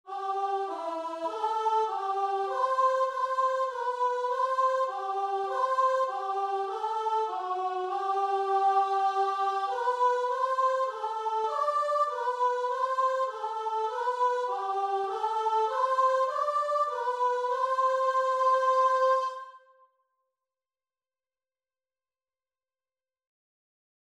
Christian Christian Guitar and Vocal Sheet Music O God, Our Help in Ages Past (St. Anne)
Free Sheet music for Guitar and Vocal
4/4 (View more 4/4 Music)
C major (Sounding Pitch) (View more C major Music for Guitar and Vocal )
Classical (View more Classical Guitar and Vocal Music)